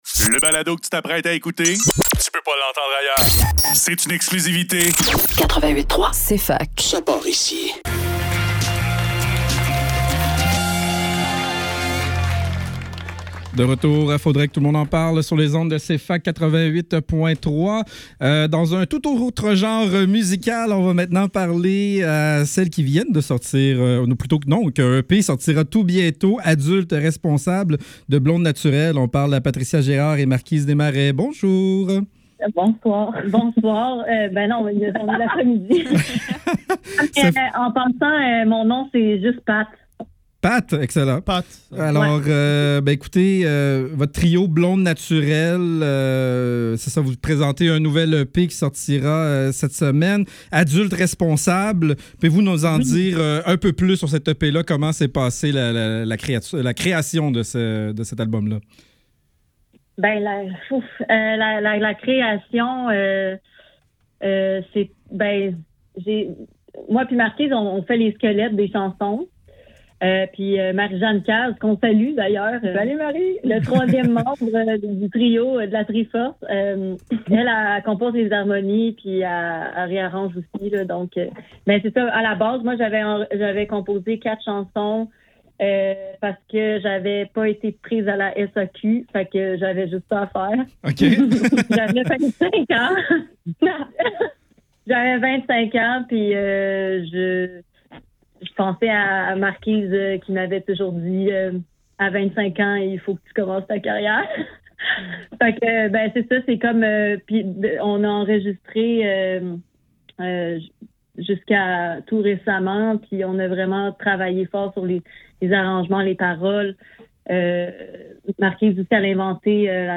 Faudrait que tout l'monde en parle - Entrevue avec Blondes Naturelles - 13 novembre 2024